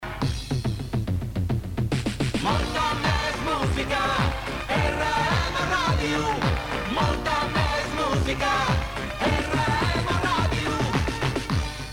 Indicatiu cantat